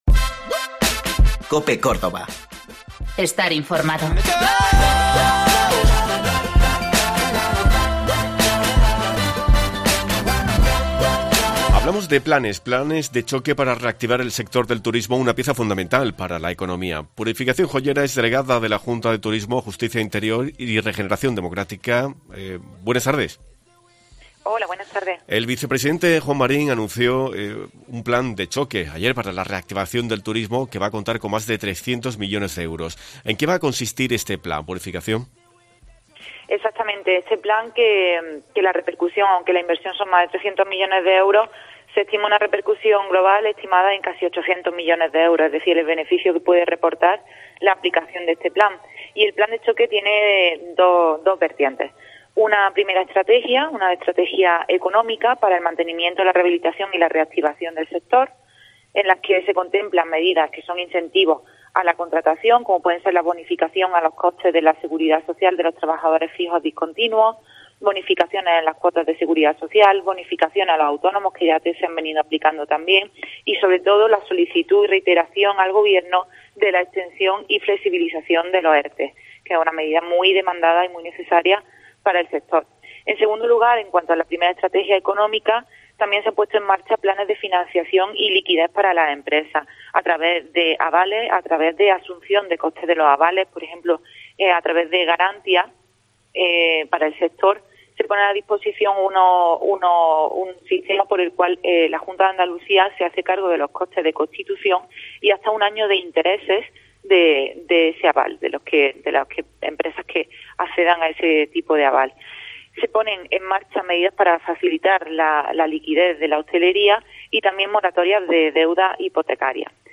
La Delegada de Turismo de la Junta en Córdoba desgrana en COPE las líneas de actuación del gobierno regional para este sector y tiende la mano a...